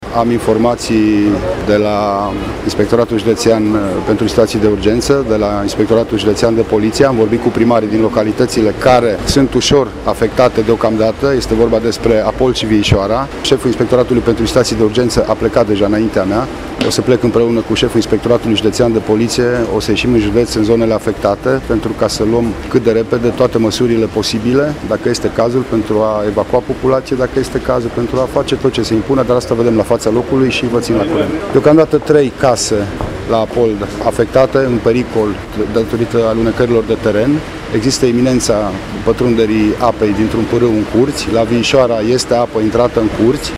Prefectul de Mureș, Lucian Goga, a declarat, la festivitățile de Ziua Drapelului Național, că a fost înștiințat de ISU despre problemele create de inundații în mai multe localități din județ, și că se va deplasa la fața locului cât de repede: